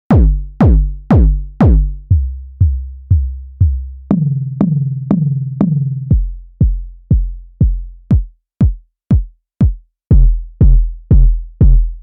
Elektron Gear Model:Cycles
Kicks